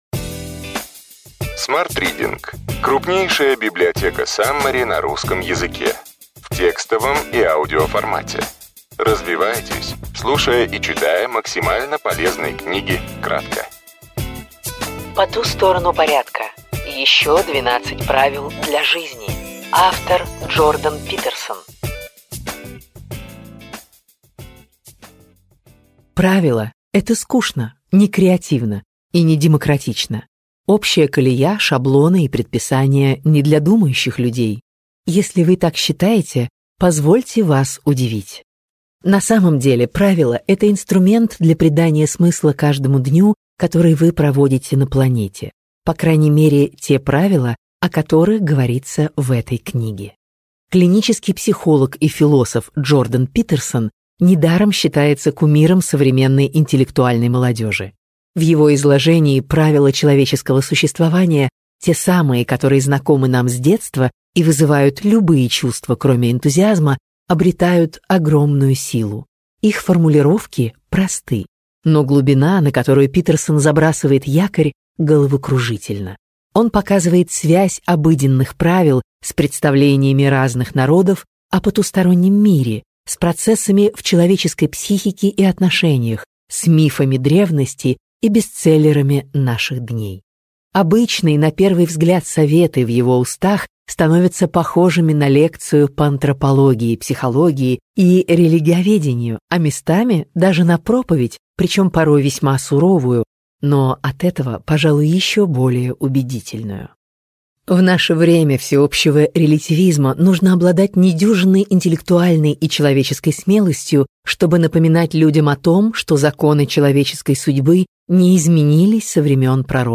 Аудиокнига Ключевые идеи книги: По ту сторону порядка.